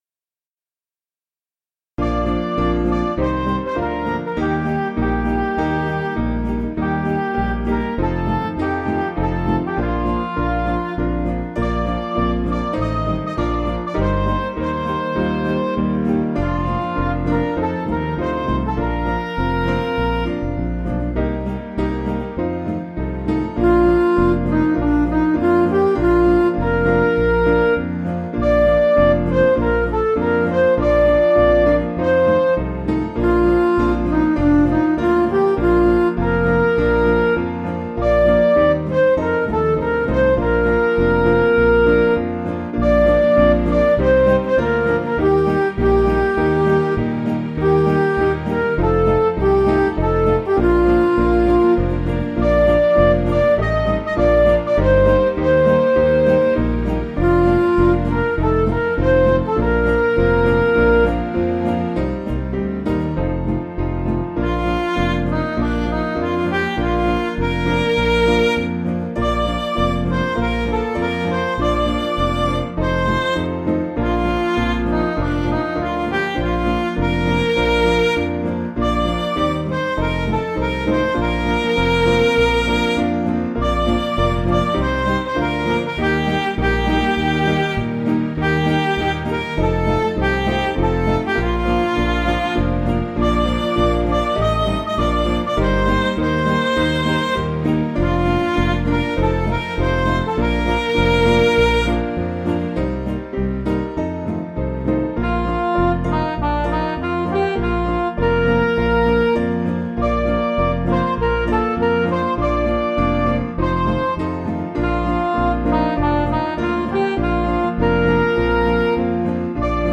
Piano & Instrumental
(CM)   4/Bb
Midi